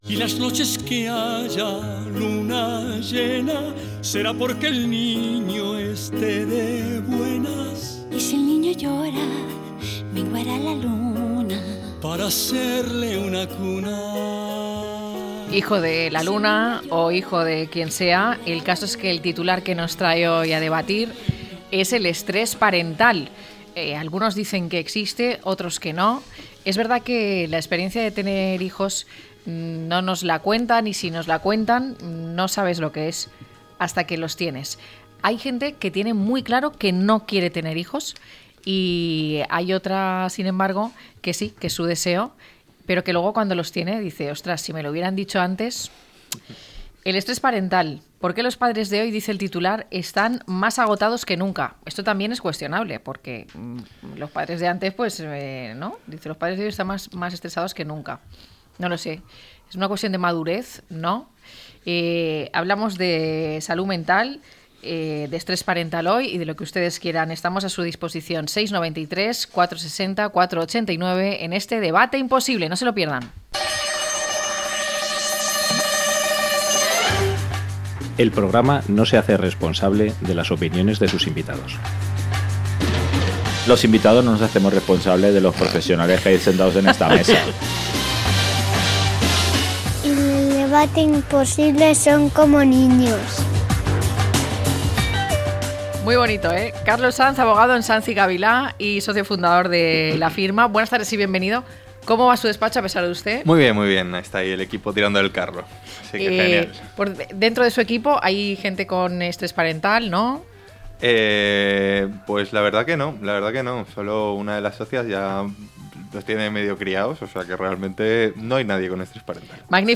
El estrés parental, a debate - La tarde con Marina